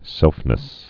(sĕlfnĭs)